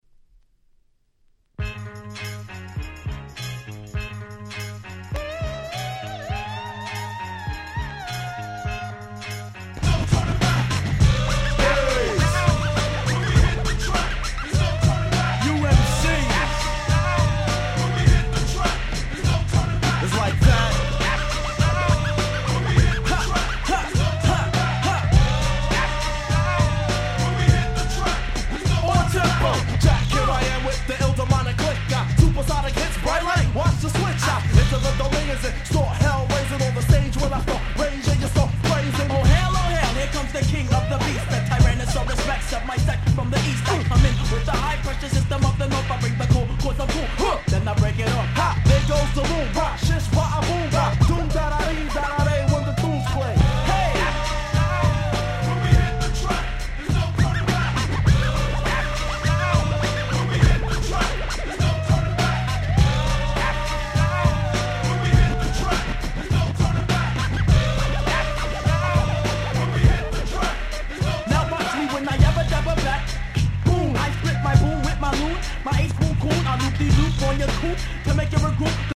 94' Nice Hip Hop !!
Hookも覚えやすいのでみんなで盛り上がれる事受け合いです。